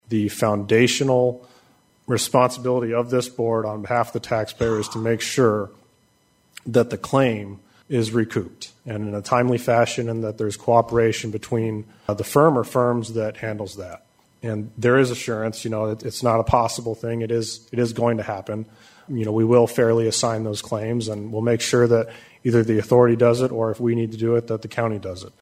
A PROGRESS REPORT AND DISCUSSION ON THE CONSTRUCTION OF THE NEW LOCAL LAW ENFORCEMENT CENTER WAS GIVEN TUESDAY AT THE WOODBURY COUNTY SUPERVISOR’S MEETING.